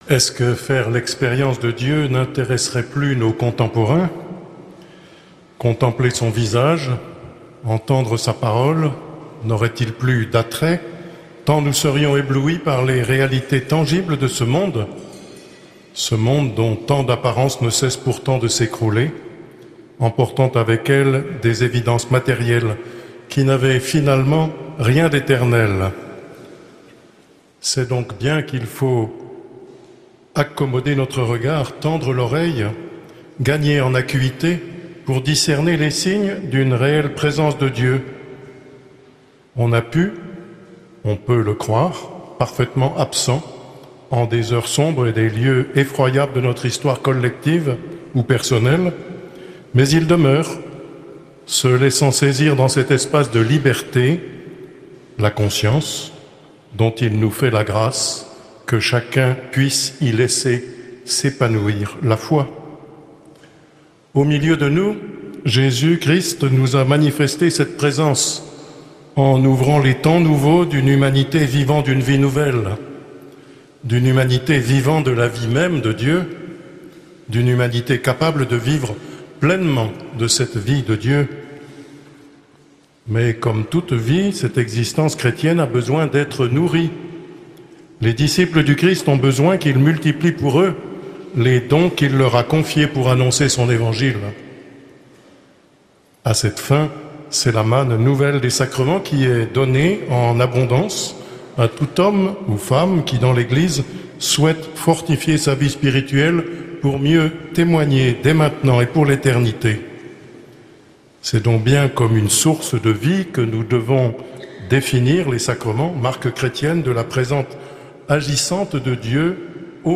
Conférences de carême